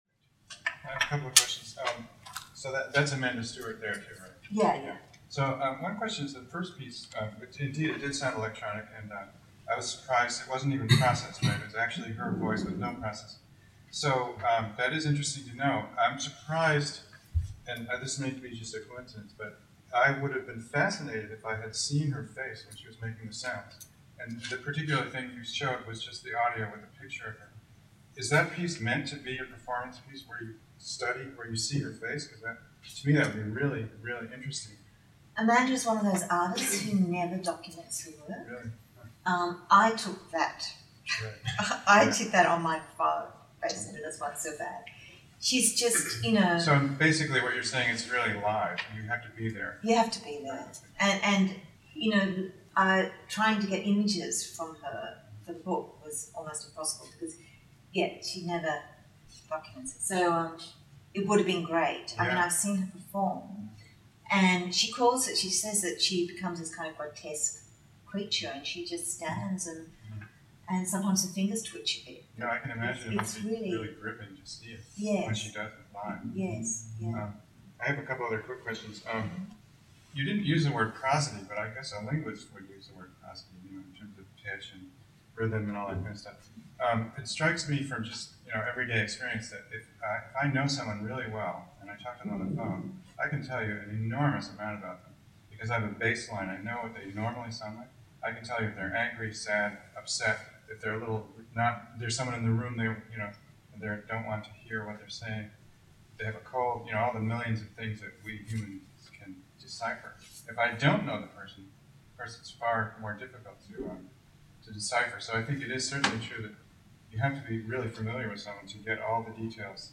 Q & A session, audio only